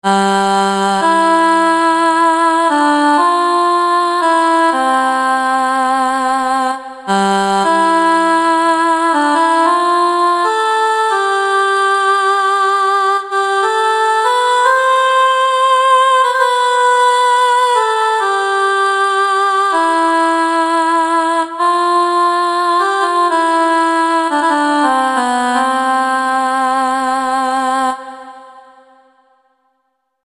A large collection of mystical Choir and Vocal sounds that will take you on a magical journey.